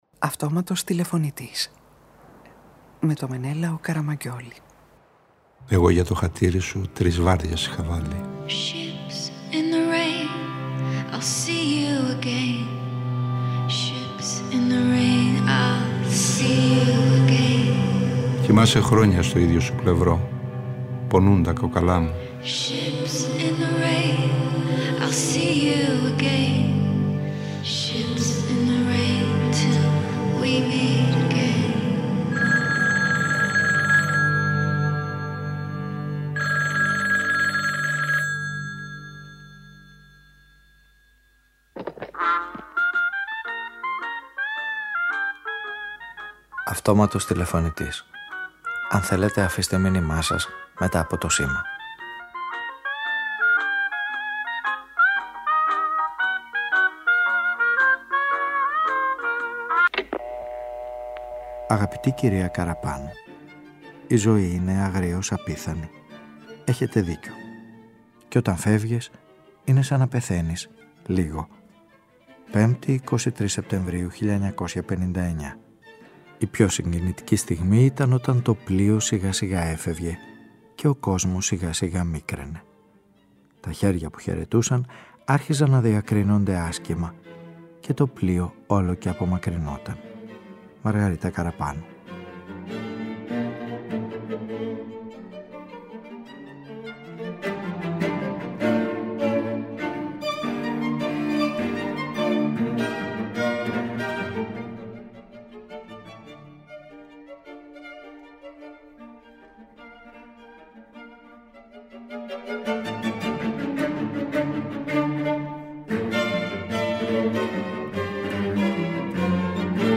Παραγωγή-Παρουσίαση: Μενέλαος Καραμαγγιώλης